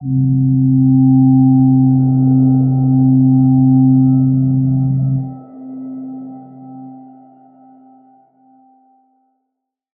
G_Crystal-C4-f.wav